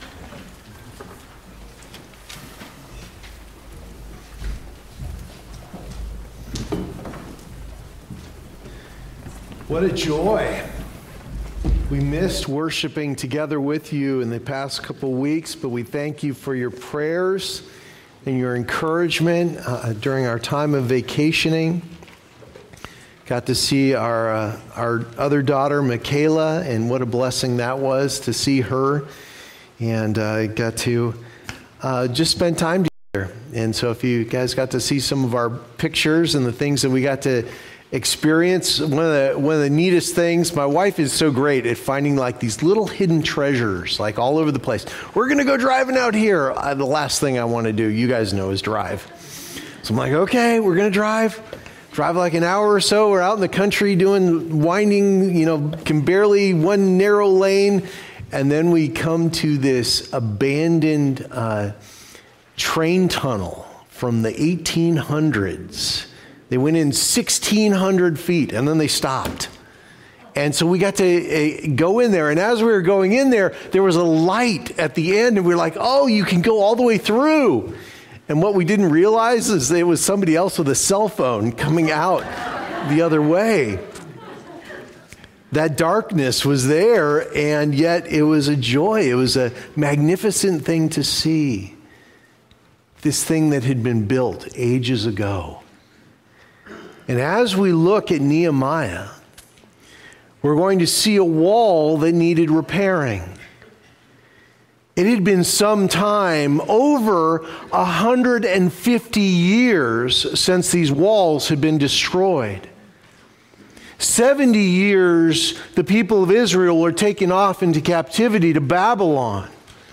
Passage: Nehemiah 2:17-20 Services: Sunday Morning Service Download Files Notes Previous Next